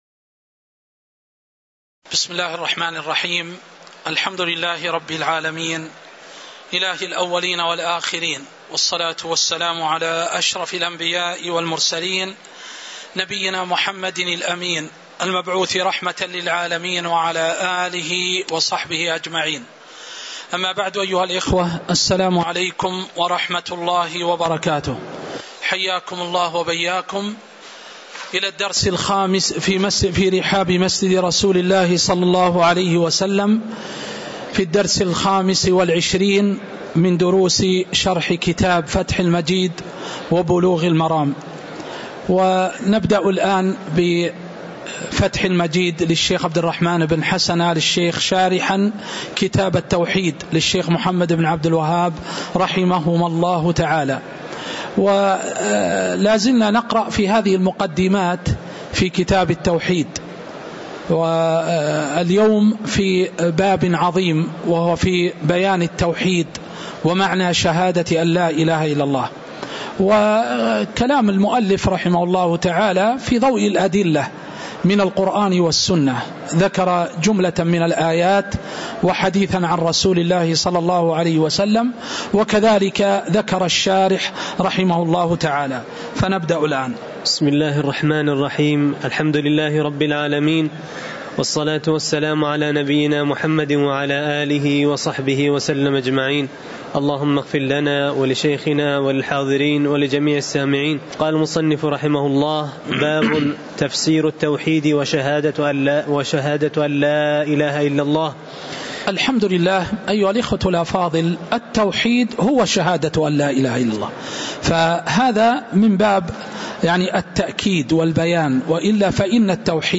تاريخ النشر ٦ ذو الحجة ١٤٤٤ هـ المكان: المسجد النبوي الشيخ